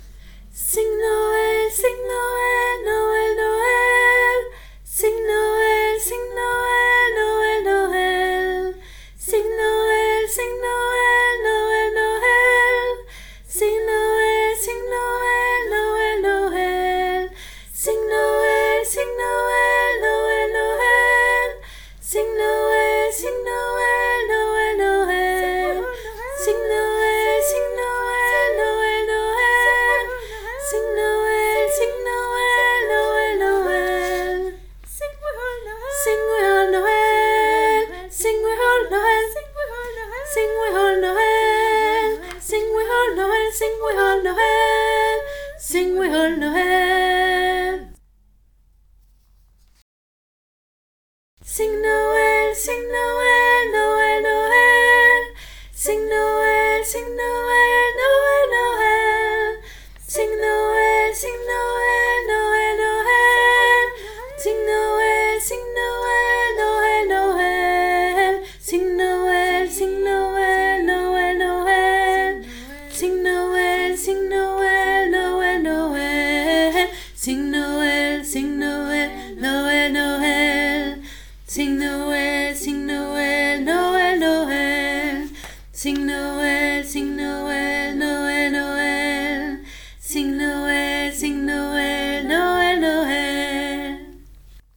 CHOEUR EPEHEMERE 2024
Sing Noel femmes
sing-noel-femmes.mp3